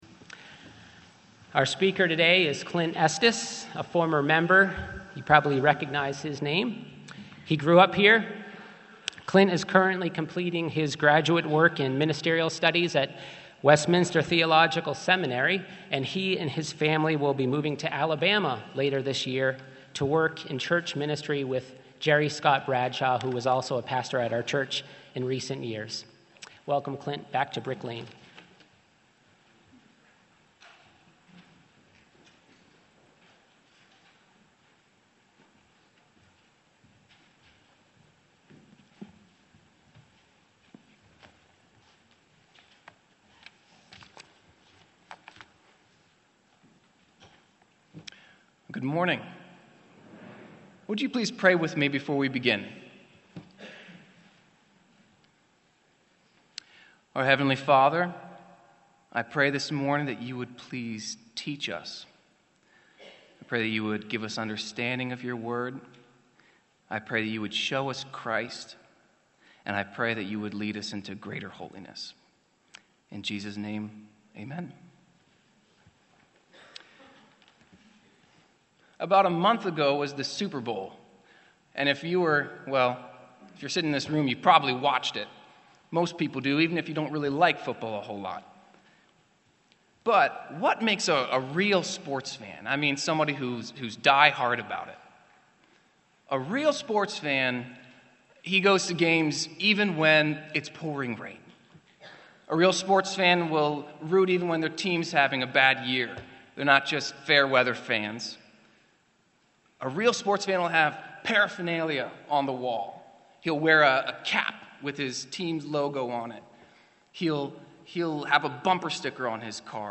Deuteronomy — Audio Sermons — Brick Lane Community Church